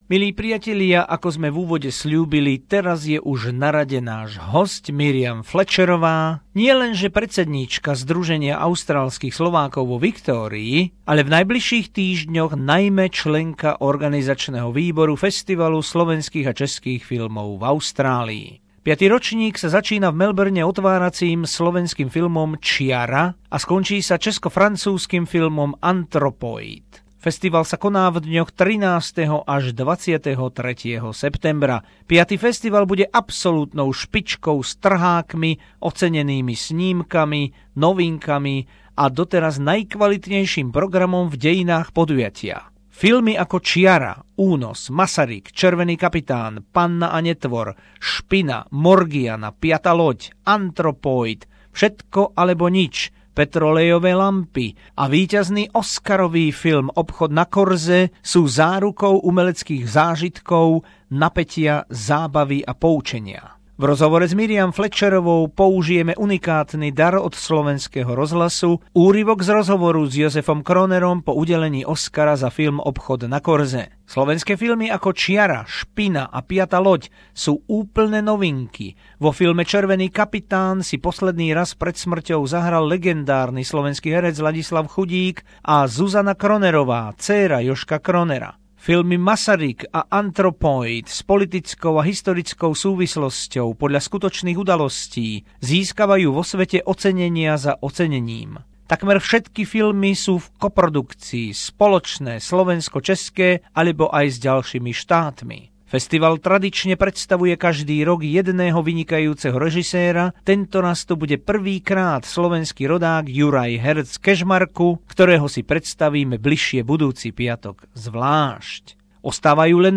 Interview
Including historical voice of Jozef Kroner after winning Oscar for the movie The Shop on the Main Street